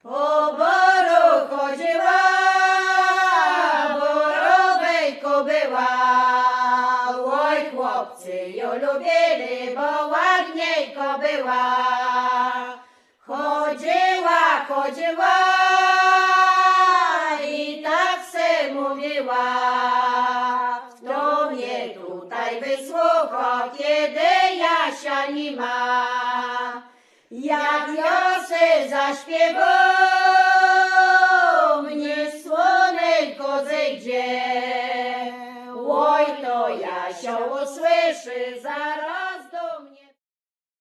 Po boru chodziła, borówejk± była She was walking in the forest (Ditties)
The CD contains archival recordings made in 1993-2007 in the area of western Roztocze (Lubelskie region) and its surrounding villages.